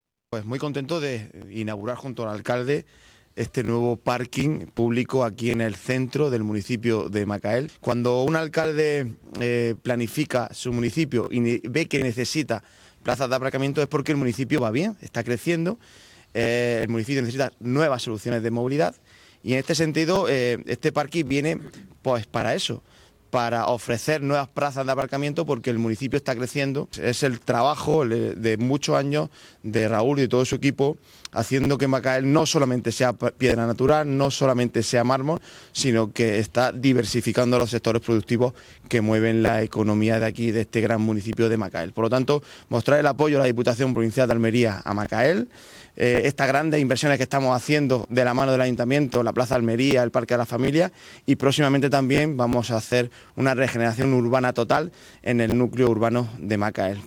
26-12_parking_macael_presidente....mp3